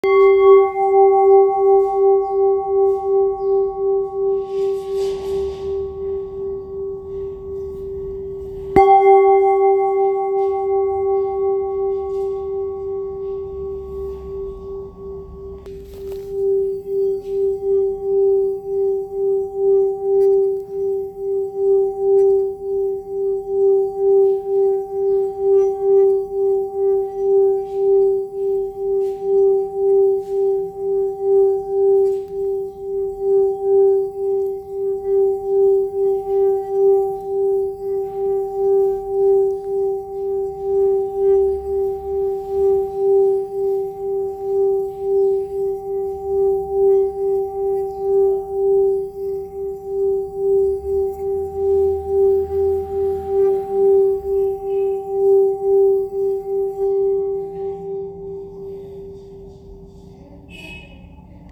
Kopre Singing Bowl, Buddhist Hand Beaten, Antique Finishing, Note J 390 Hz
Material Seven Bronze Metal